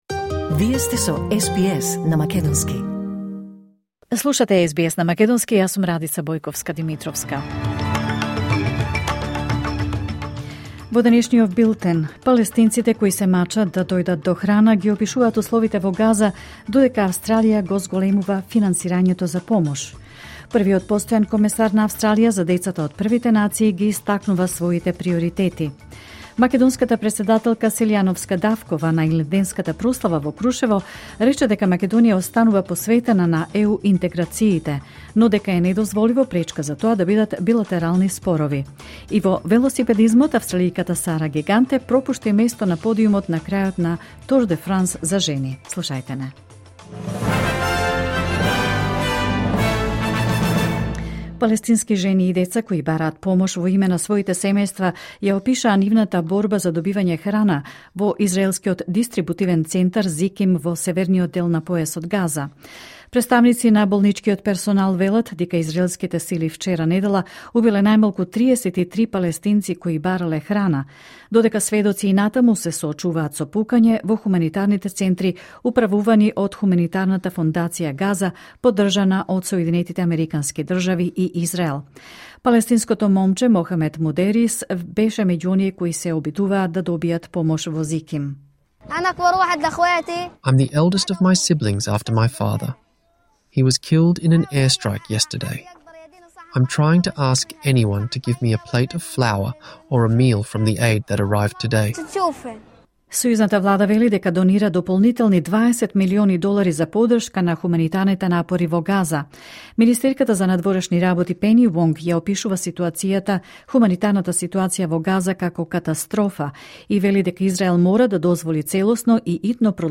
Вести на СБС на македонски 4 август 2025